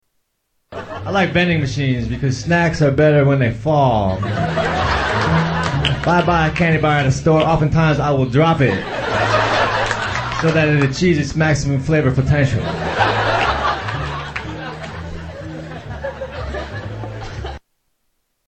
Category: Comedians   Right: Personal
Tags: Comedians Mitch Hedberg Sounds Mitchell Lee Hedberg Mitch Hedberg Clips Stand-up Comedian